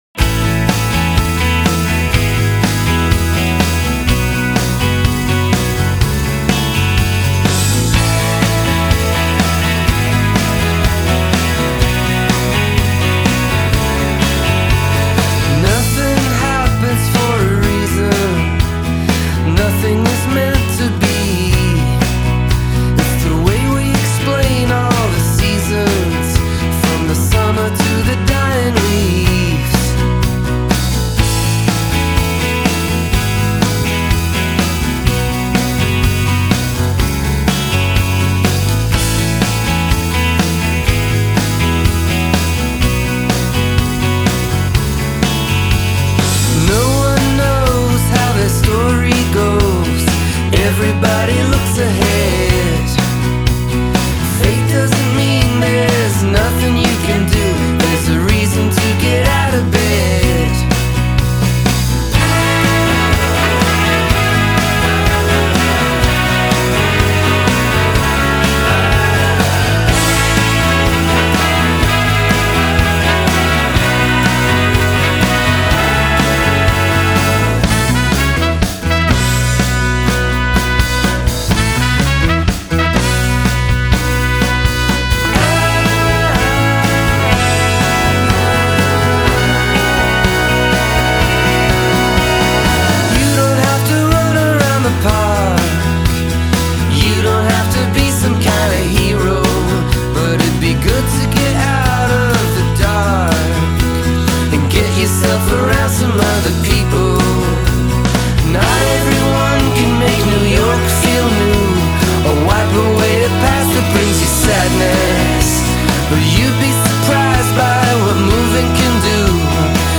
sparkling pop-rock
wonderfully written and horn-heavy